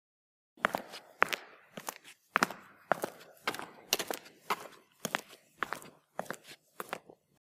human
Footsteps On Concrete